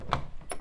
VW-related Sounds
Door Opening Beetle door opening
vw_door_open.wav